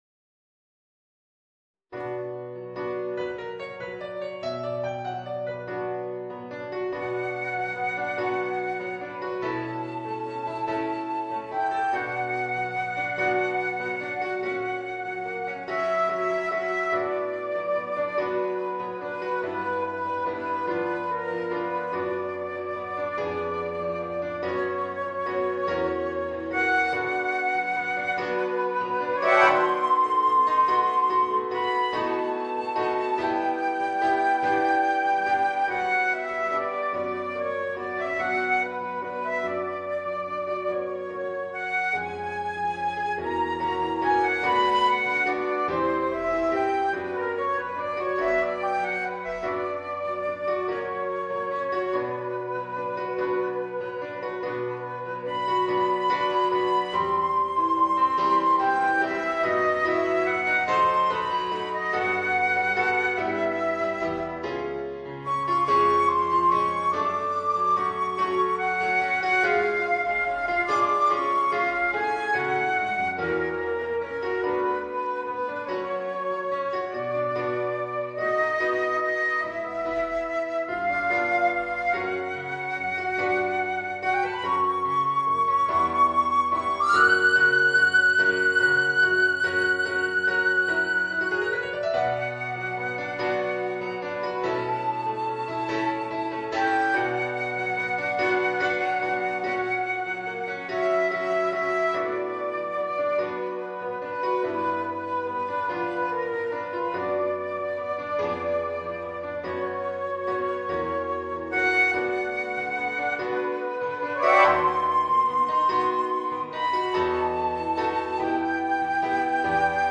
Voicing: Flute and Piano